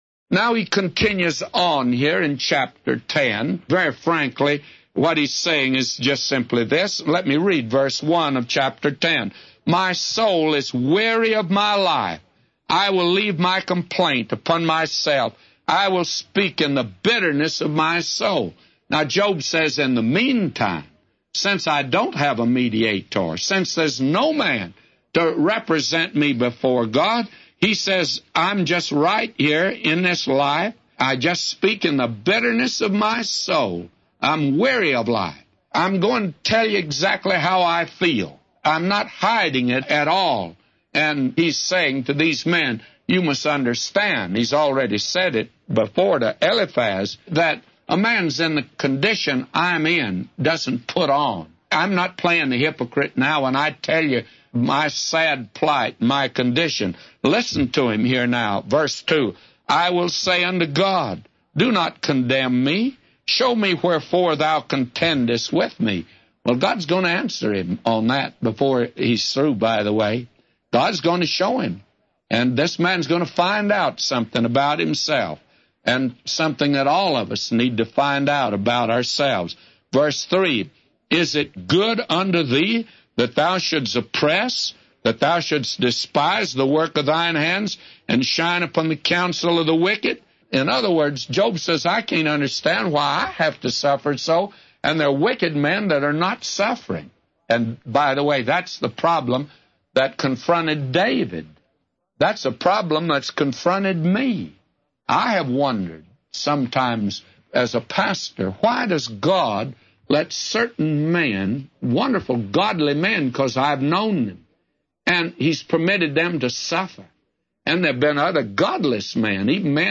A Commentary By J Vernon MCgee For Job 10:1-999